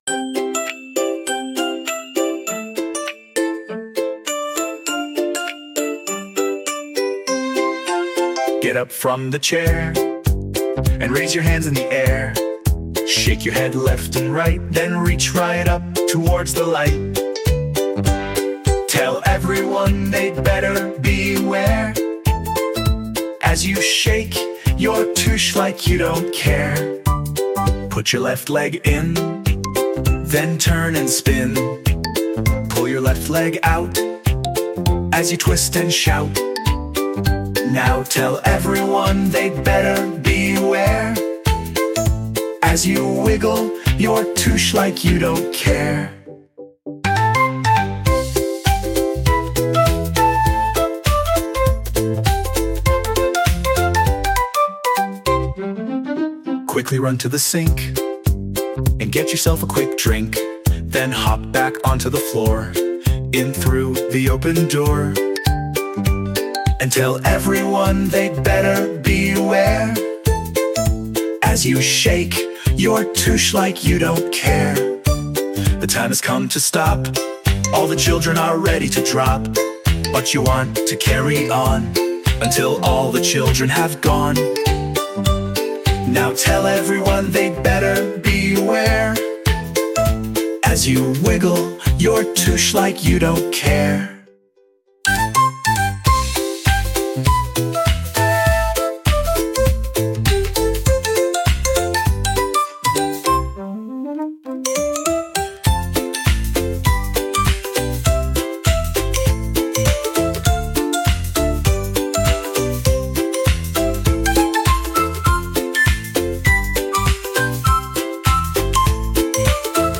a lively and playful children’s song